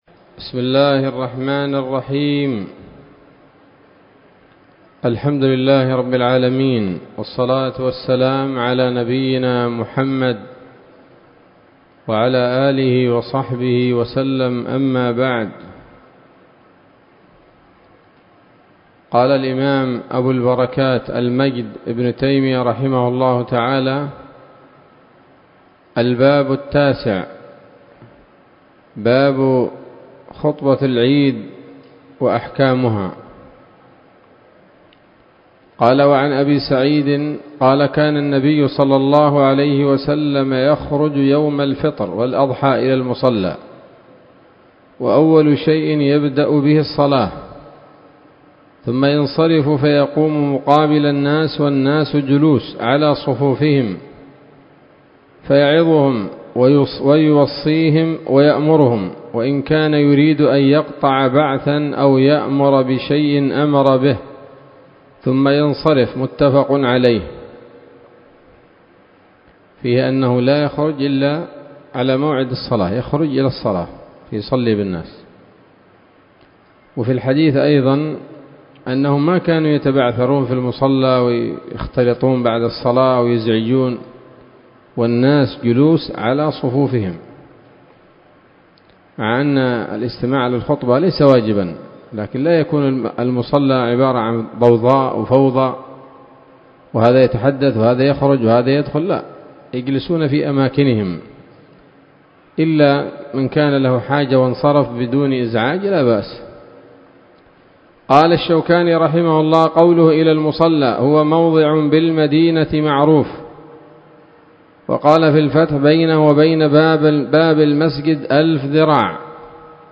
الدرس الرابع عشر من ‌‌‌‌كتاب العيدين من نيل الأوطار